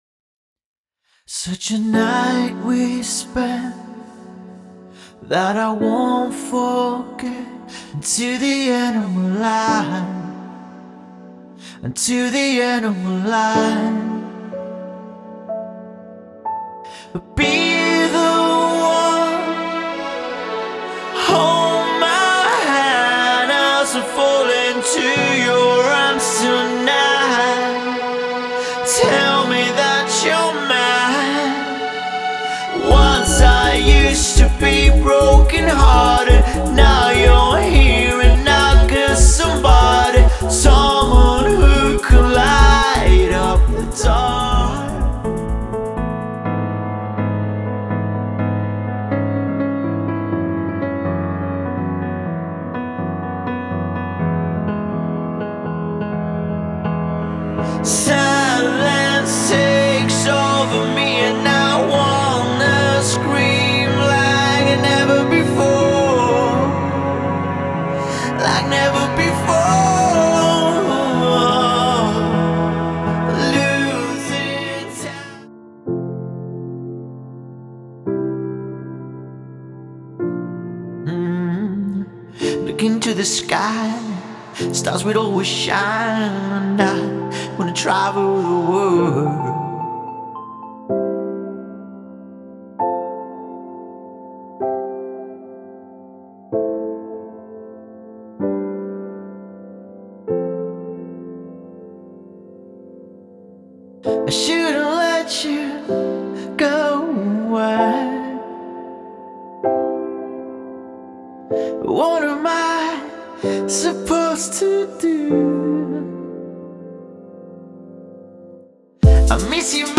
EDM